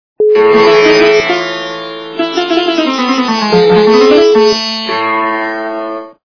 - звуки для СМС